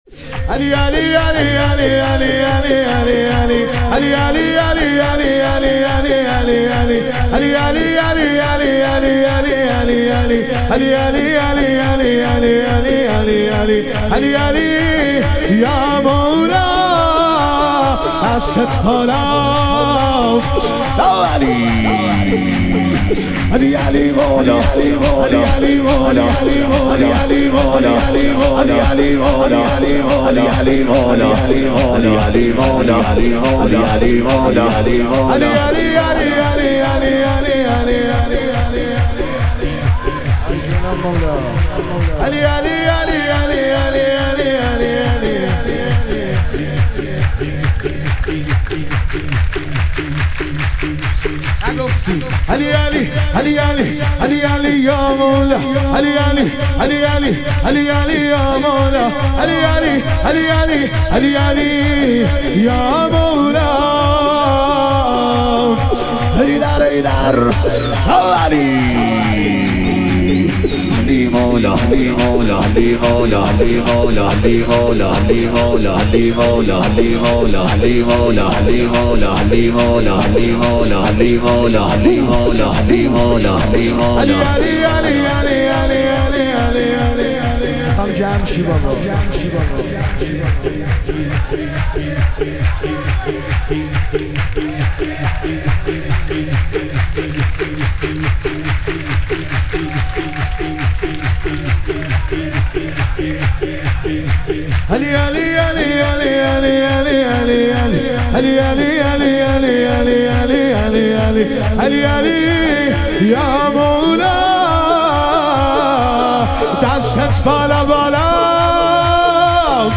ظهور وجود مقدس رسول اکرم و امام صادق علیهم السلام - شور